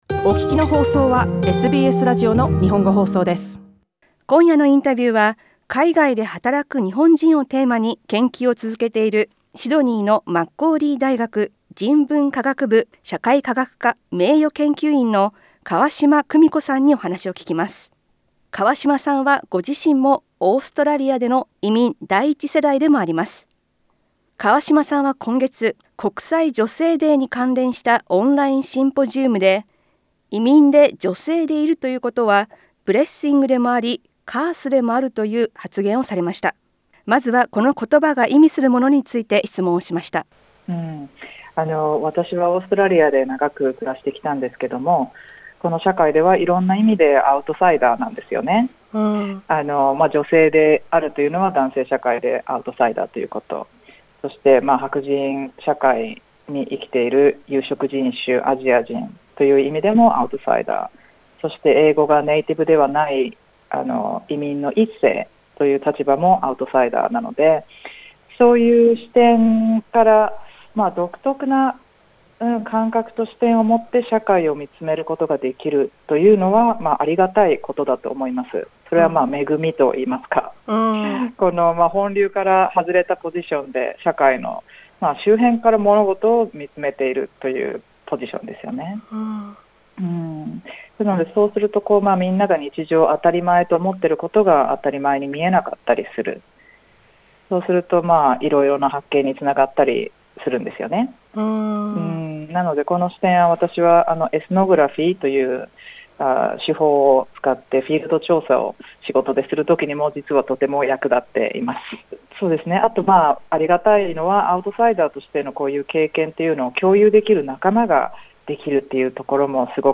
インタビューでは、オーストラリアで女性の移民として働くなかでの自身の業界での経験や、一般的に女性移民が経験する「壁」について、そして自身の研究対象となったオーストラリアの日本人ワーキングホリデー利用者などについて聞きました。